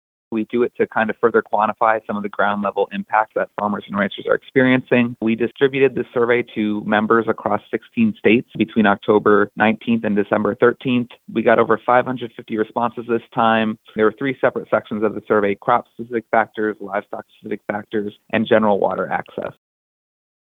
Audio provided as a service to farm broadcasters by the American Farm Bureau Federation